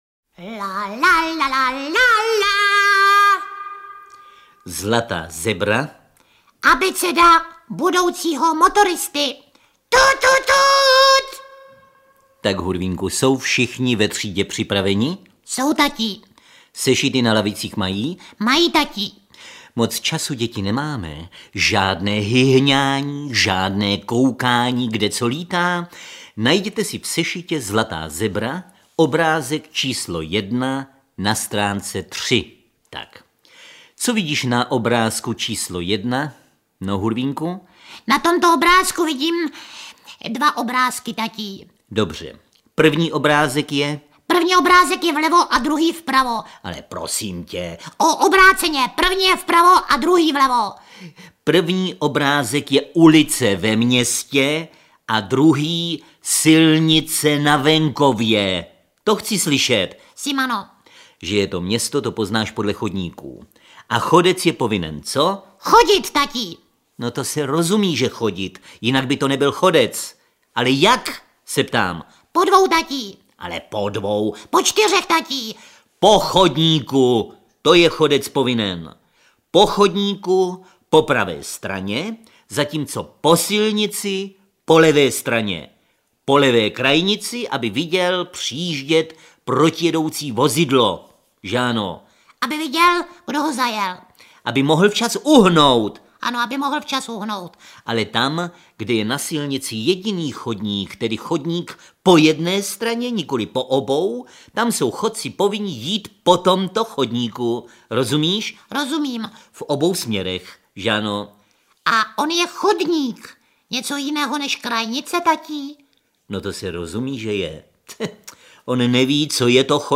Ukázka z knihy
spejbl-a-hurvinek-zlata-zebra-audiokniha